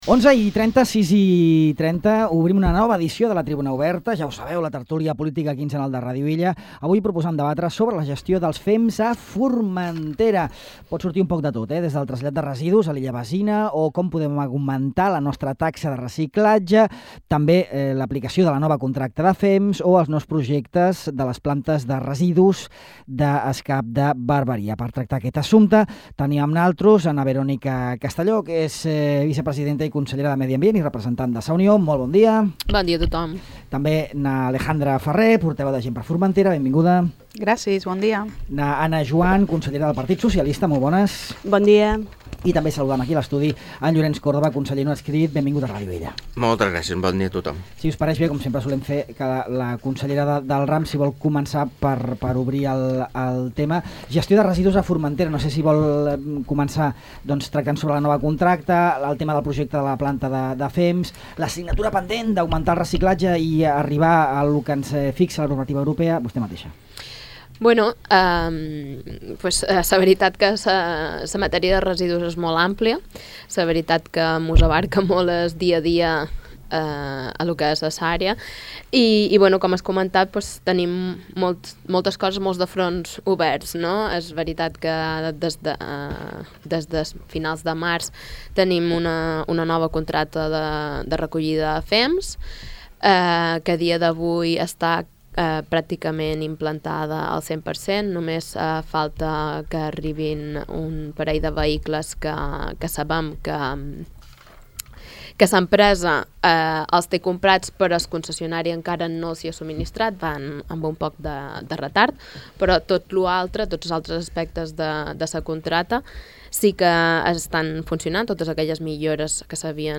Verónica Castelló, consellera de Medi Ambient i representant de Sa Unió; Alejandra Ferrer, portaveu de GxF; Ana Juan, consellera del PSOE; i Llorenç Córdoba, conseller no adscrit, participen en aquesta nova edició de la Tribuna Oberta, la tertúlia política quinzenal de Ràdio Illa, avui dedicada a la gestió dels fems a Formentera.